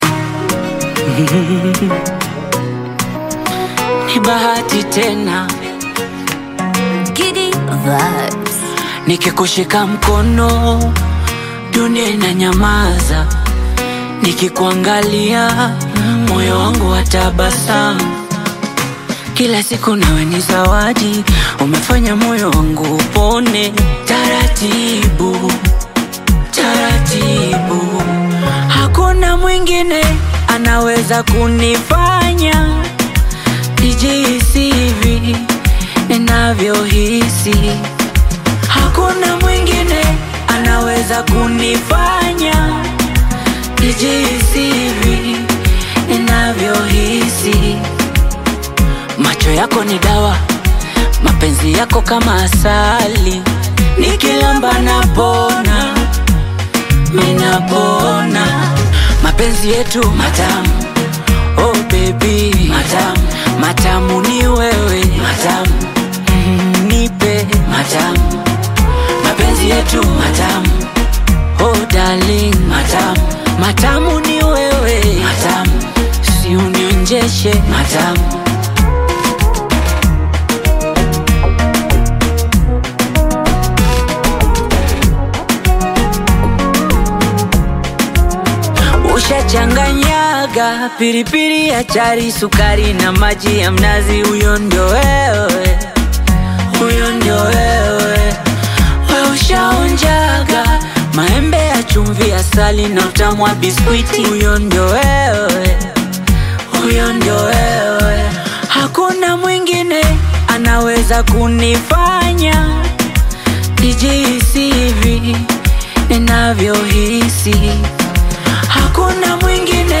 AfrobeatAudioKenyan Music
romantic Afro‑Pop single